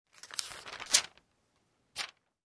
На этой странице собраны звуки журналов: шелест страниц, перелистывание, легкие постукивания обложки.
Звук перелистываемой страницы журнала